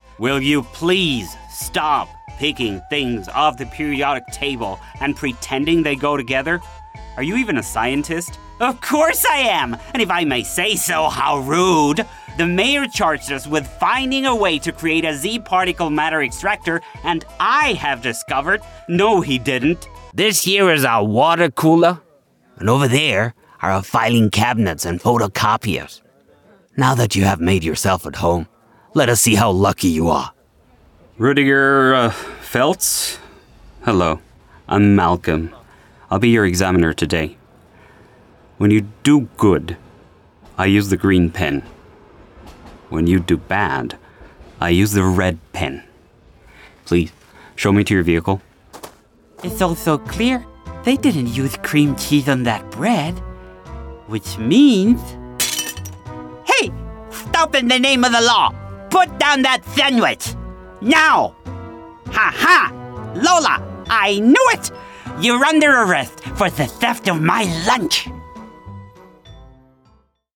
Male
Authoritative, Character, Confident, Friendly, Versatile
Voice reels
Microphone: Austrian Audio OC18, Shure SM7B, Austrian Audio CC8, t.bone RM 700